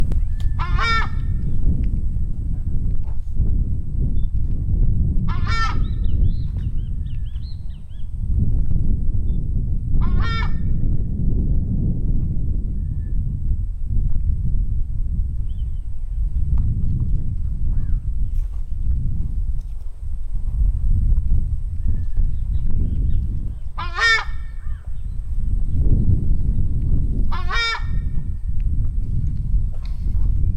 Tachã (Chauna torquata)
Nome em Inglês: Southern Screamer
Fase da vida: Adulto
Localidade ou área protegida: Bañados cerca del rio san francisco
Condição: Selvagem
Certeza: Fotografado, Gravado Vocal